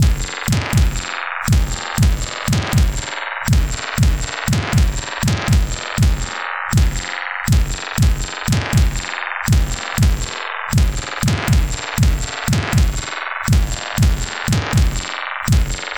Abstract Rhythm 24.wav